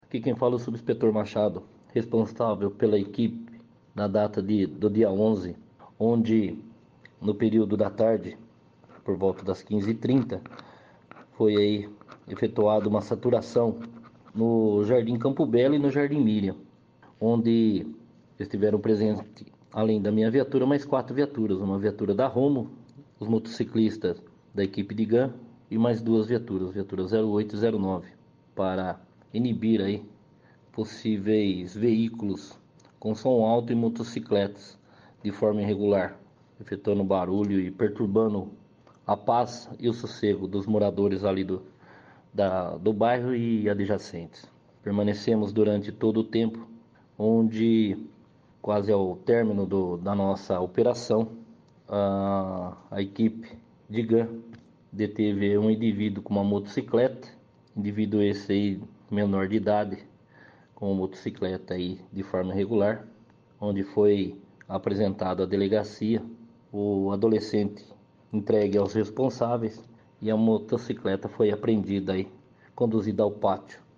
Rádio Clube • 101,7 FM 🔴 AO VIVO